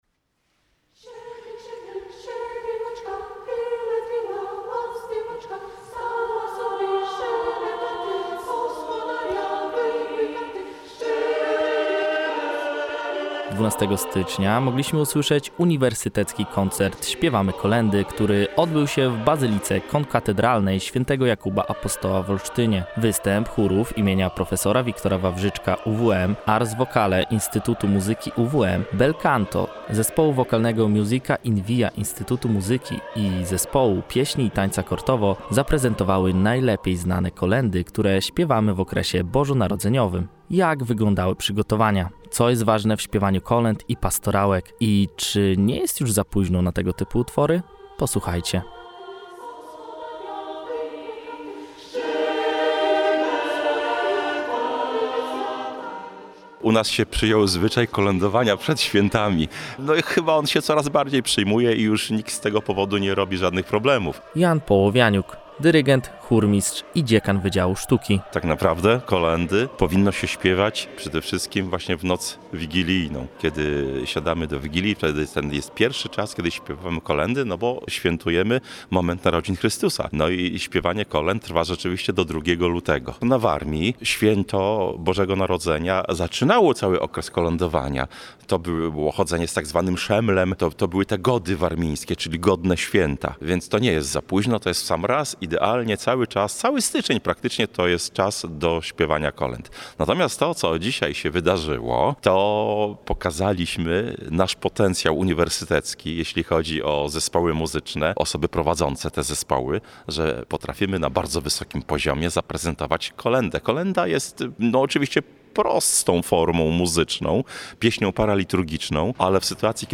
Doroczny Uniwersytecki Koncert „Śpiewajmy Kolędy”, który odbył się w bazylice konkatedralnej św. Jakuba w niedzielę 12 stycznia, okazał się wyjątkowym wydarzeniem, pełnym pięknych kolęd i świątecznej atmosfery.
1301-Spiewajmy-Koledy.mp3